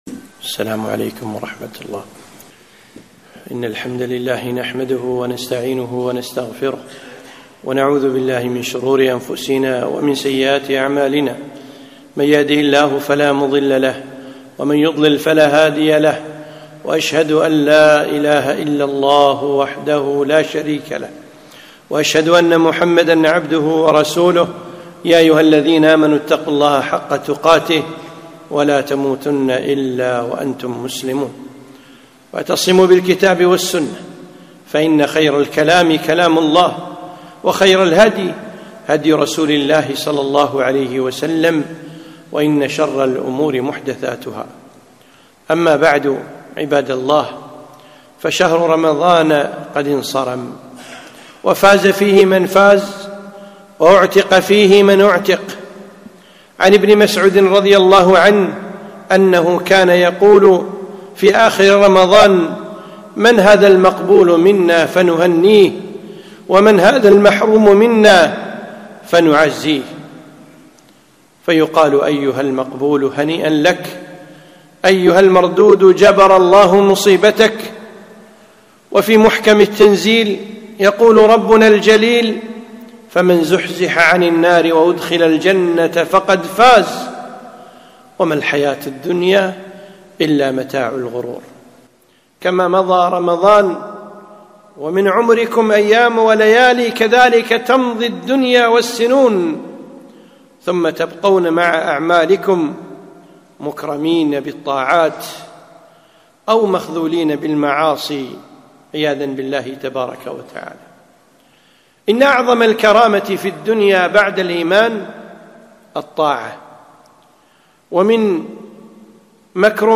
خطبة - الاستقامة بعد رمضان